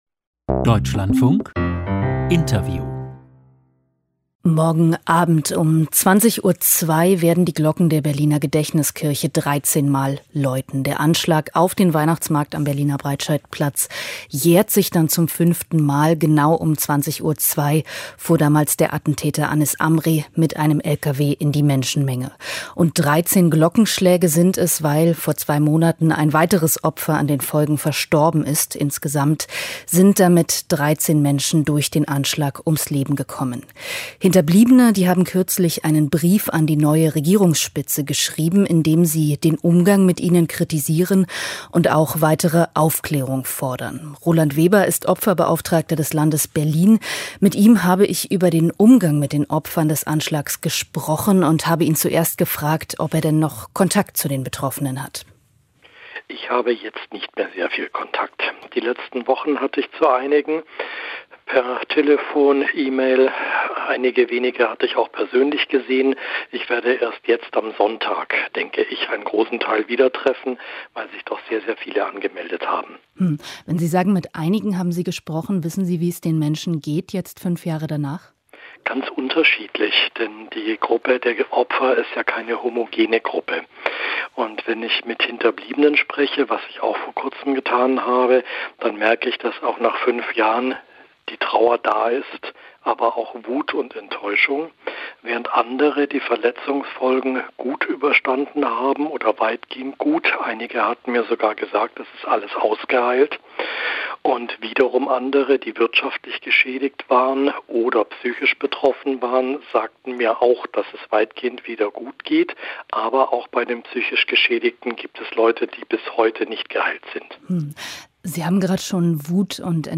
Anschlag Breitscheidplatz: Interview Roland Weber, Opferbeauftragter Berlin